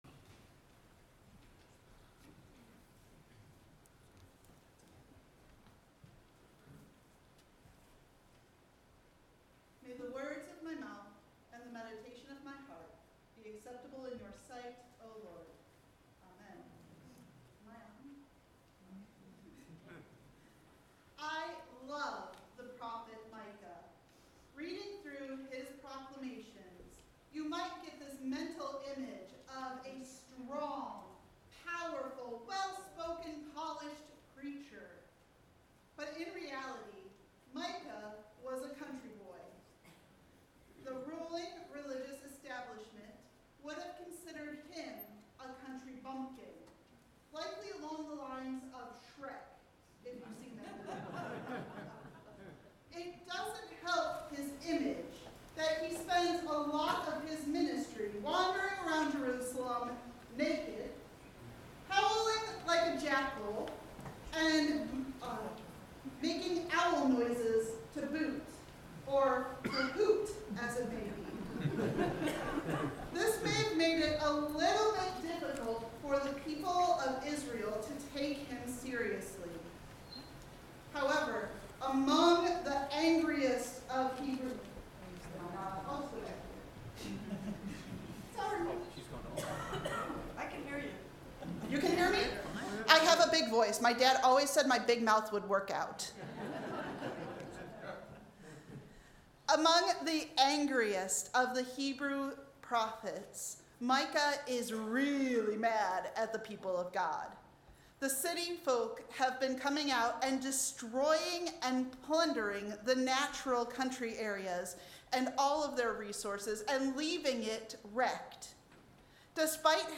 Sermon Podcast: February 1, 2026